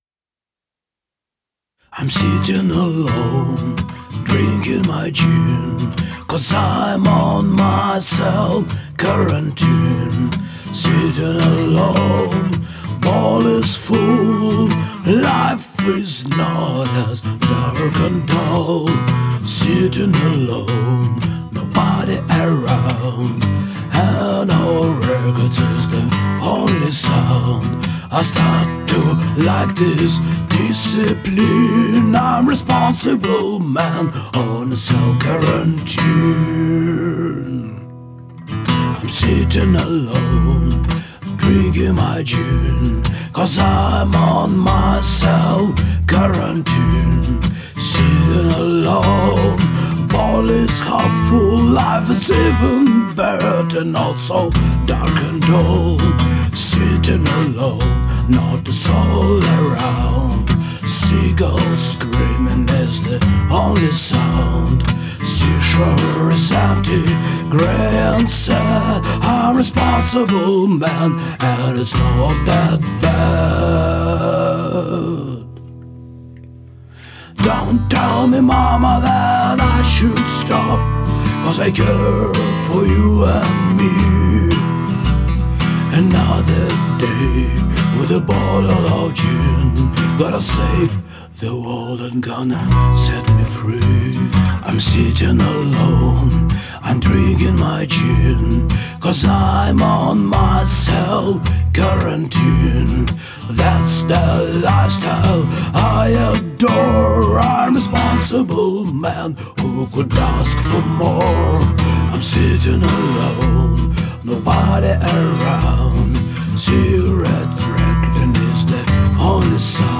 Responsible Man (On a self-Quarantine), blues
Простенький блюз, две акустики, бас и голос, но мне понравилось то, что получилось в итоге.